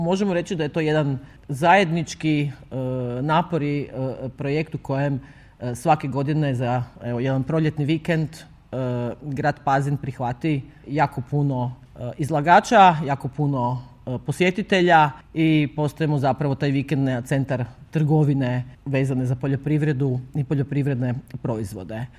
Gradonačelnica Grada Pazina Suzana Jašić podsjetila je da je riječ o manifestaciji koja je iz godine u godinu rasla zajedno s gradom i njegovim gospodarstvom.